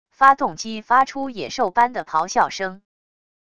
发动机发出野兽般的咆哮声wav音频